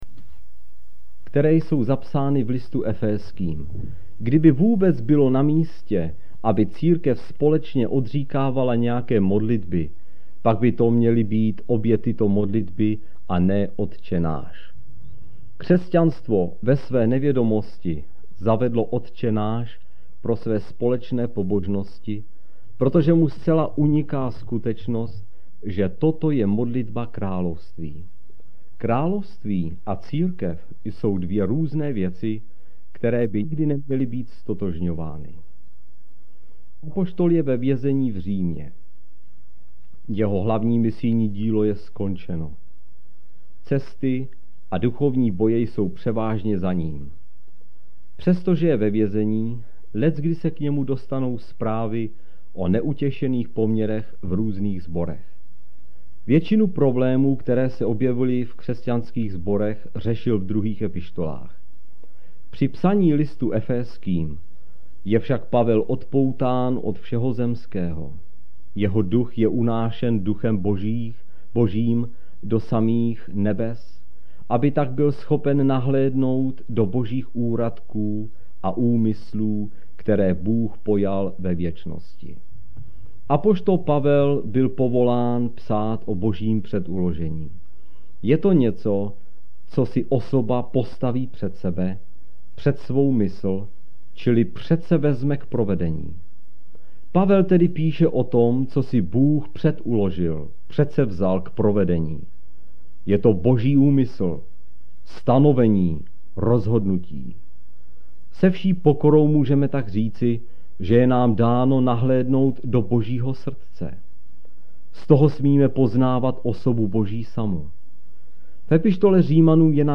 Proslovy z TWR -> Pavlova modlitba